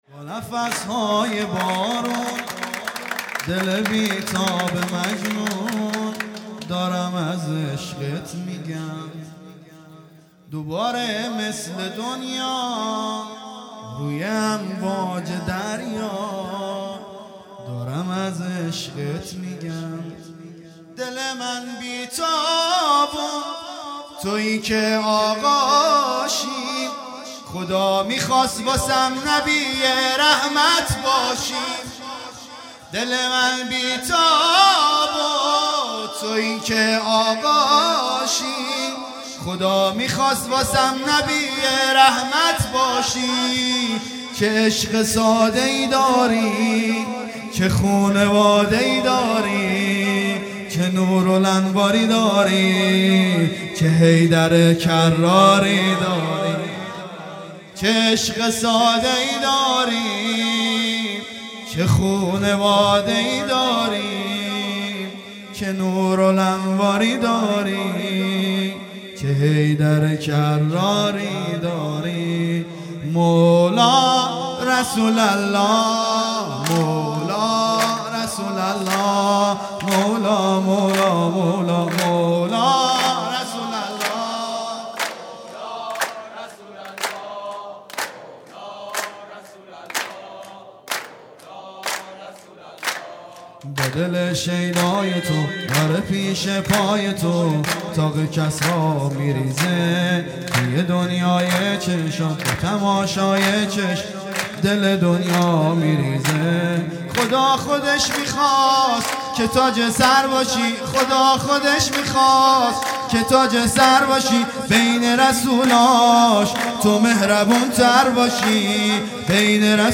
هیئت دانشجویی فاطمیون دانشگاه یزد
سرود
ولادت پیامبر (ص) و امام صادق (ع) | ۳ آذر ۱۳۹۷